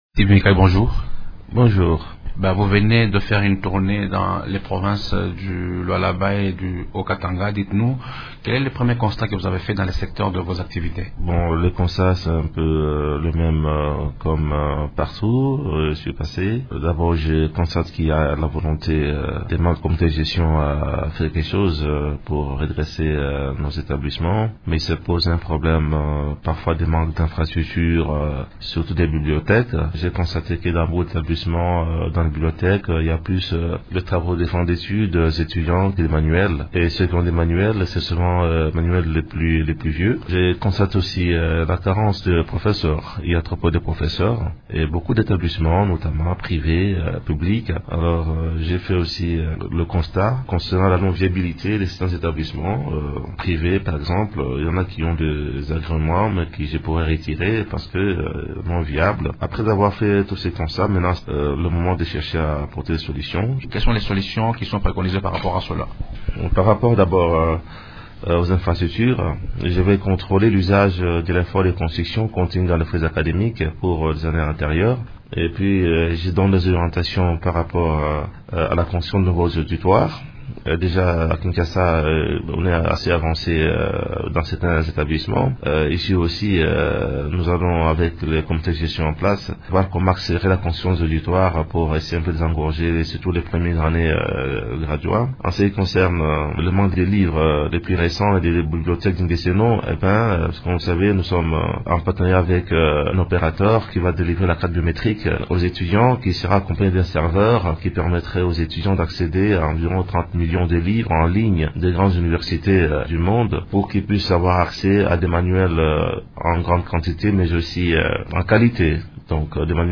Steve Mbikayi s’entretient